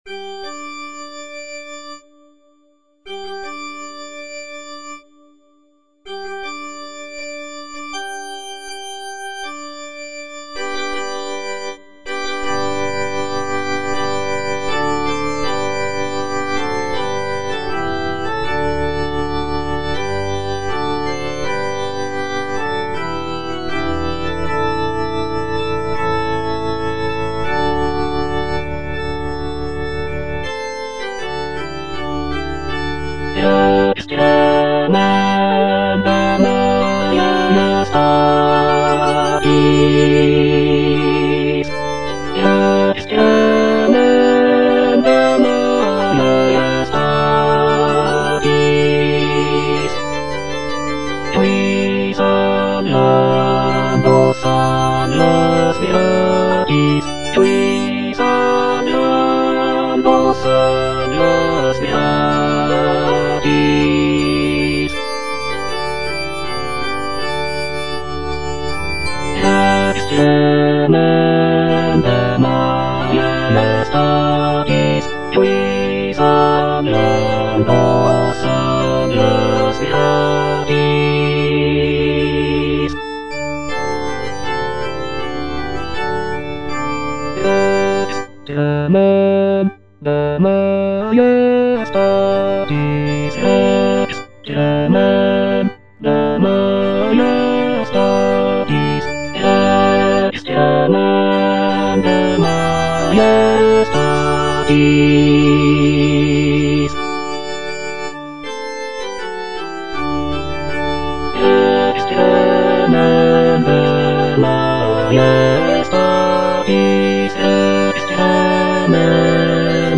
(bass I) (Emphasised voice and other voices)
is a sacred choral work rooted in his Christian faith.